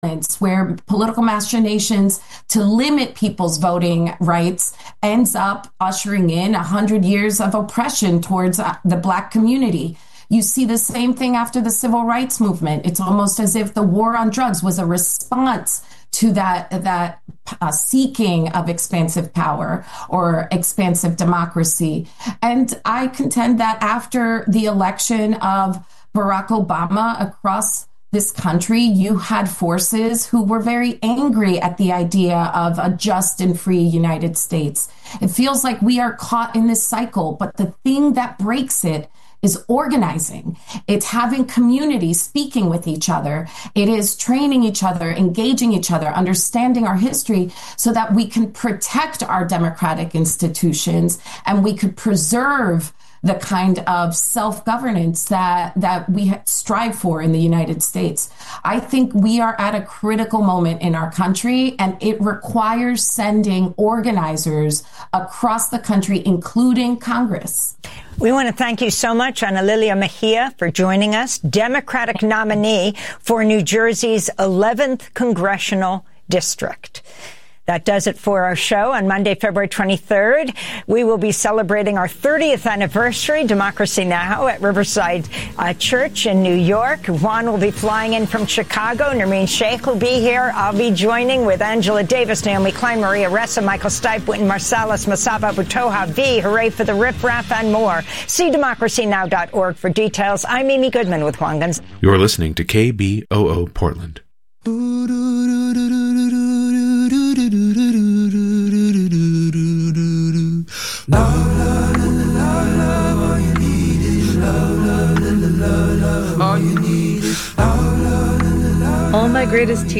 Hosted by: KBOO News Team
Non-corporate, community-powered, local, national and international news